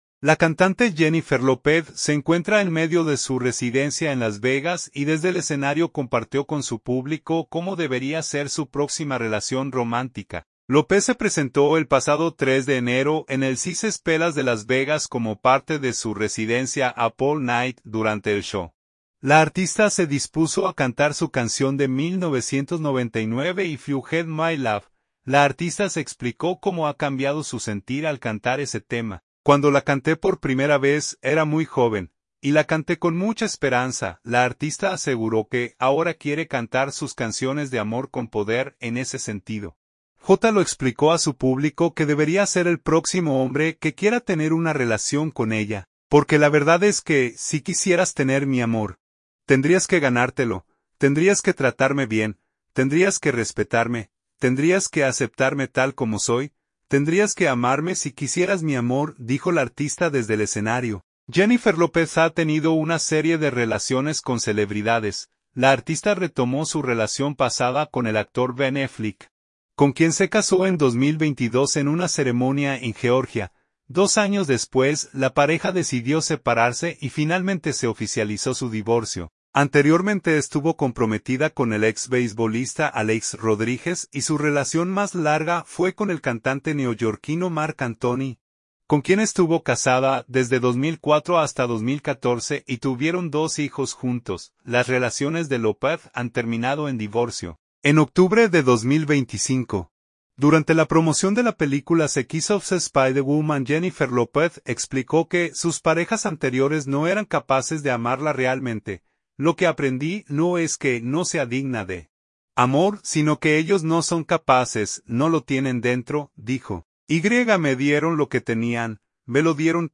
La cantante Jennifer Lopez se encuentra en medio de su residencia en Las Vegas y desde el escenario compartió con su público cómo debería ser su próxima relación romántica.
Lopez se presentó el pasado 3 de enero en el Caesars Palace de Las Vegas como parte de su residencia ‘UP ALL NIGHT’.